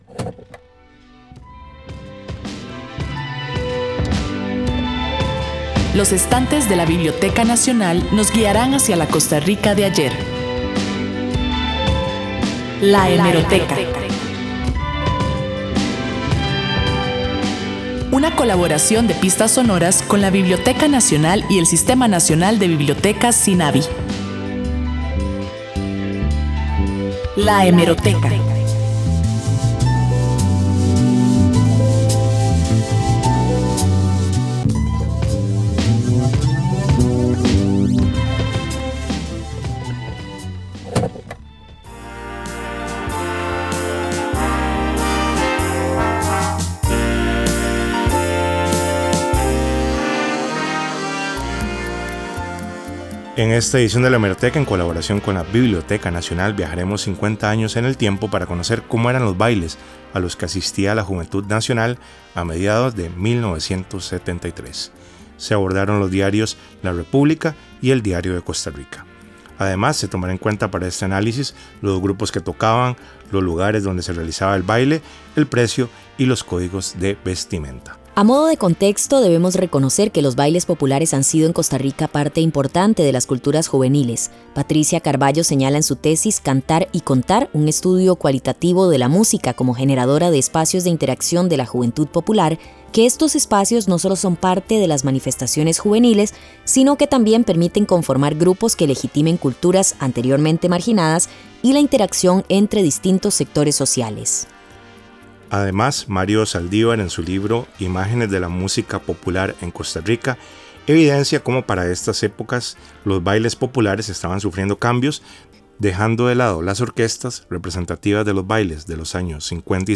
Sección de la Biblioteca Nacional en el Programa Pistas Sonoras de Radio Universidad, transmitido el 9 de setiembre 2023. Este espacio es una coproducción de las Radios de la Universidad de Costa Rica y la Biblioteca Nacional con el propósito de difundir la cultura costarricense.